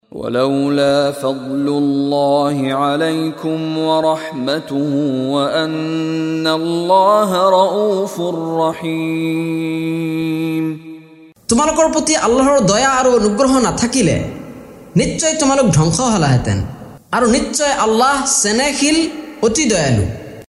লগতে ক্বাৰী মিশ্বাৰী ৰাশ্বিদ আল-আফাছীৰ কণ্ঠত তিলাৱত।